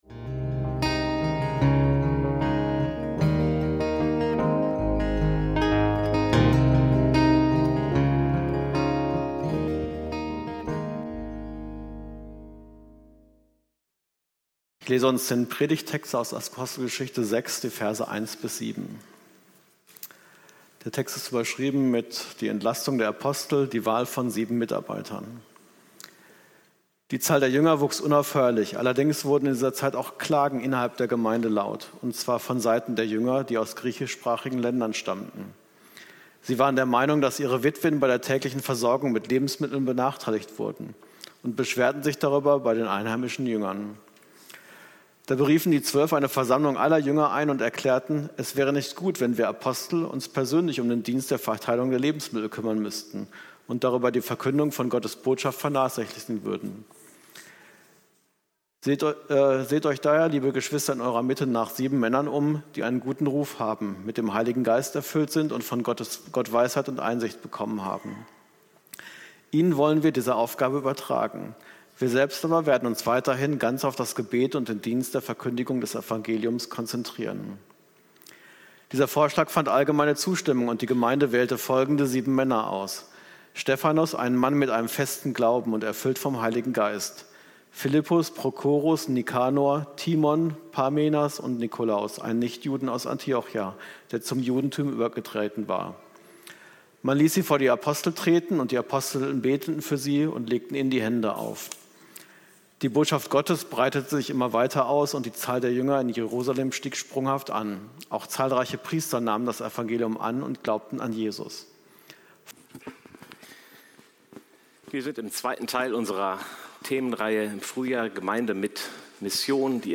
Dienen und Leiten - Gemeinde mit Mission - Predigt vom 01.02.2026